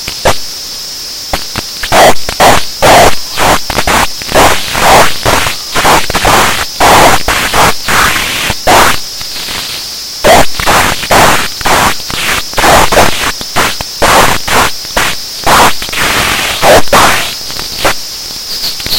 ultrasound base frequency modulation speaking through a speaker.
ultrasound audacity pro modulation jack and jill
ultrasound-pro-modulation-jack-and-jill.mp3